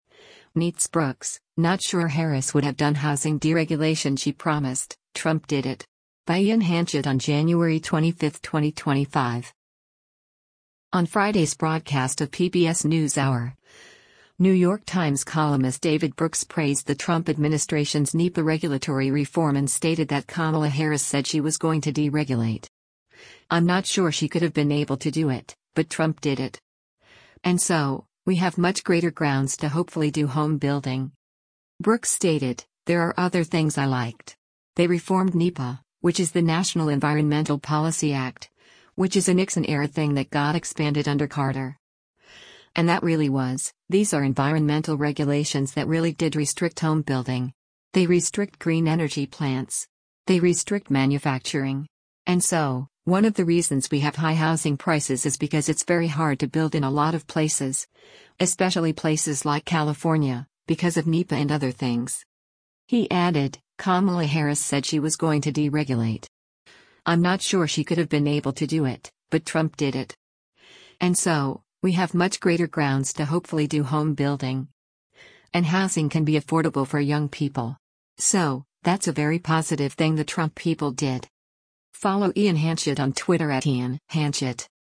On Friday’s broadcast of “PBS NewsHour,” New York Times columnist David Brooks praised the Trump administration’s NEPA regulatory reform and stated that “Kamala Harris said she was going to deregulate. I’m not sure she could have been able to do it, but Trump did it. And so, we have much greater grounds to hopefully do home building.”